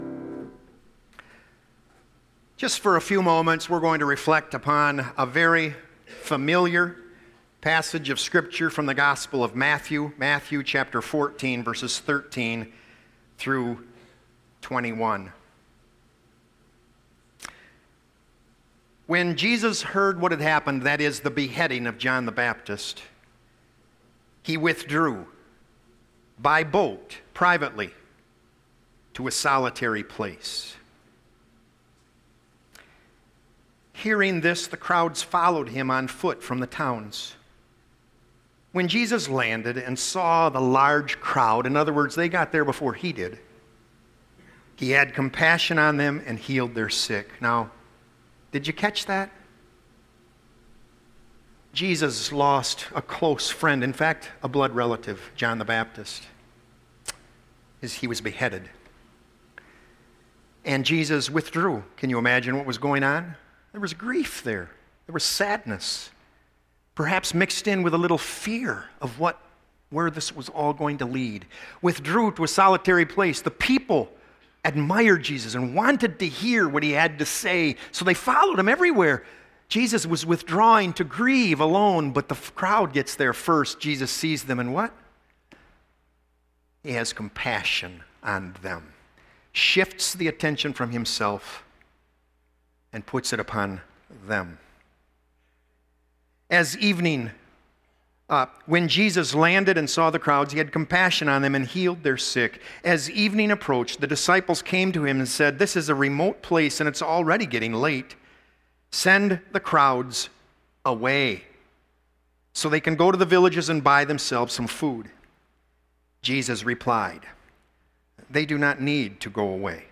Sermons | Woodhaven Reformed Church